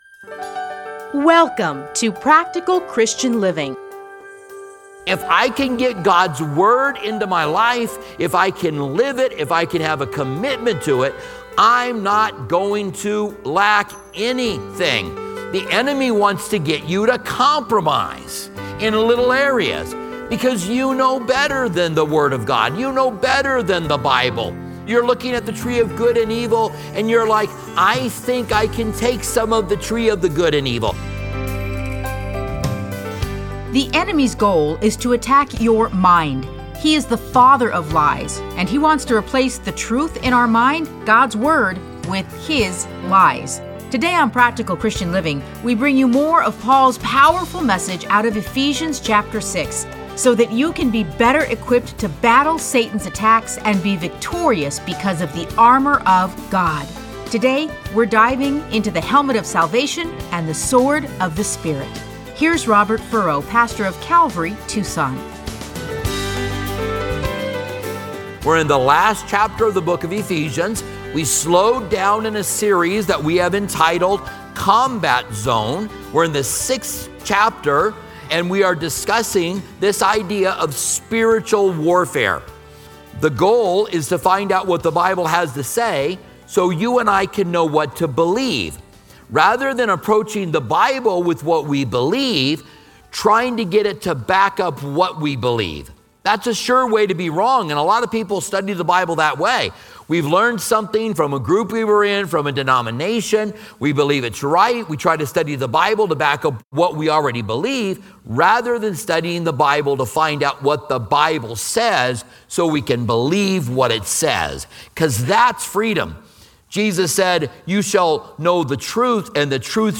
teachings are edited into 30-minute radio programs titled Practical Christian Living. Listen to a teaching from Ephesians 6:17.